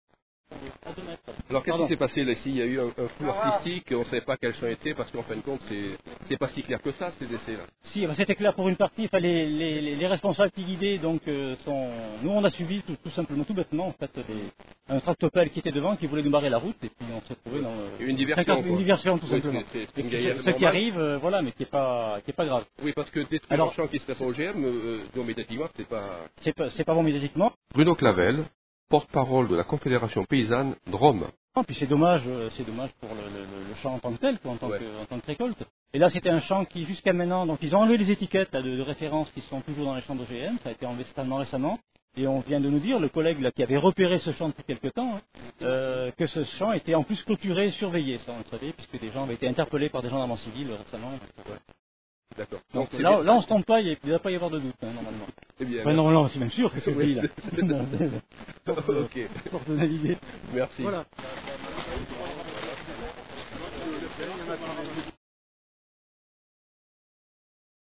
Les Interviews de Radio-Méga
le 26 Août 2001 à Salette